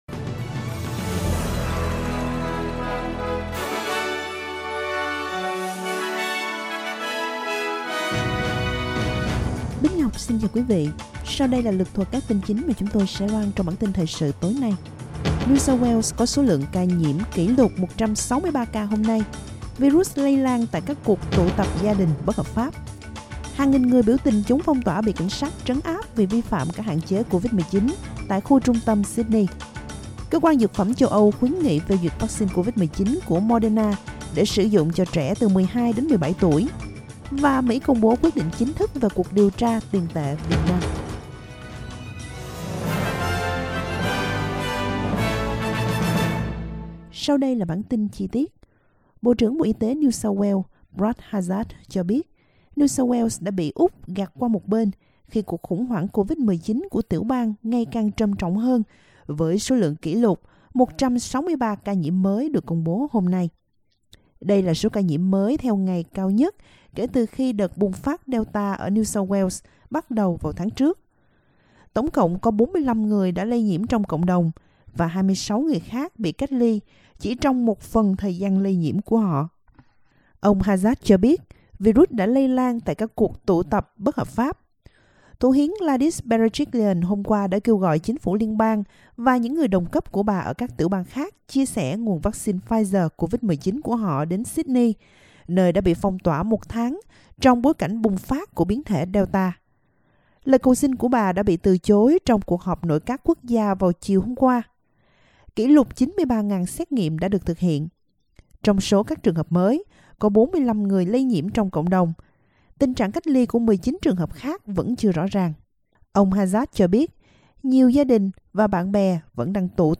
Vietnamese news bulletin Source: Getty
vietnamese_news1_247.mp3